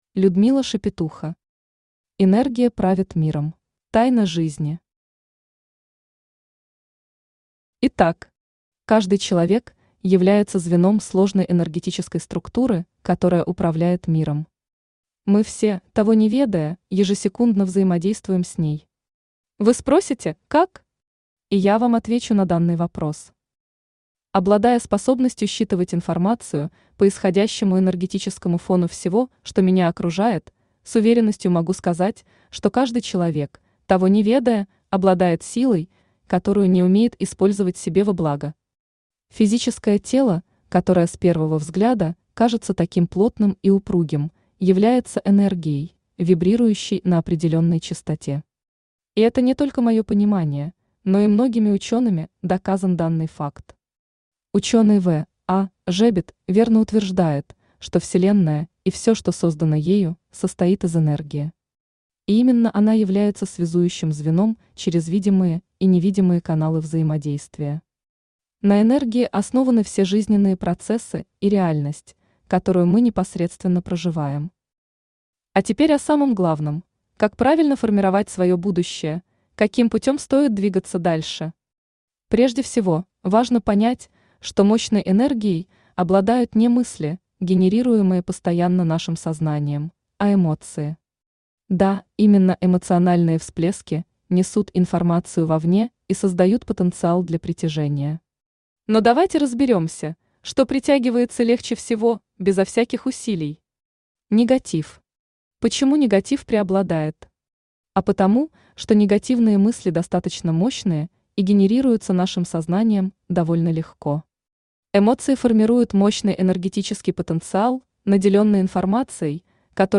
Аудиокнига Энергия правит миром | Библиотека аудиокниг
Aудиокнига Энергия правит миром Автор Людмила Шепетуха Читает аудиокнигу Авточтец ЛитРес.